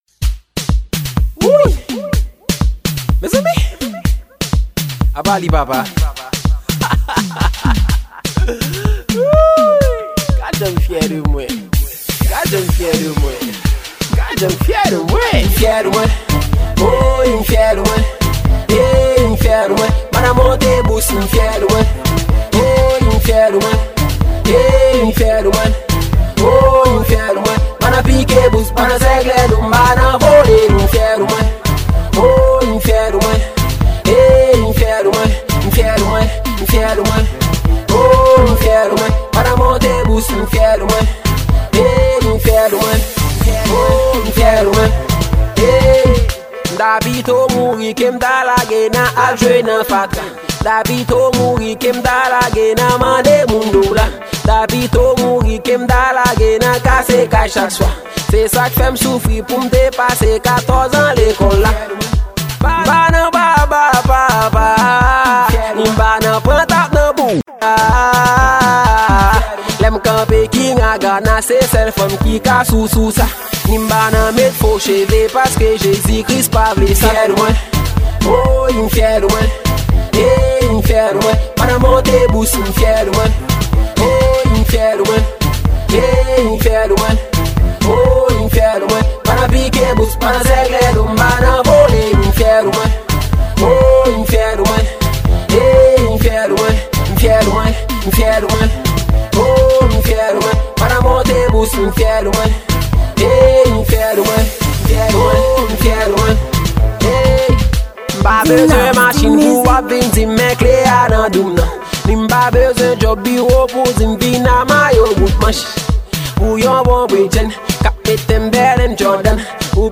Genre: Afro Style.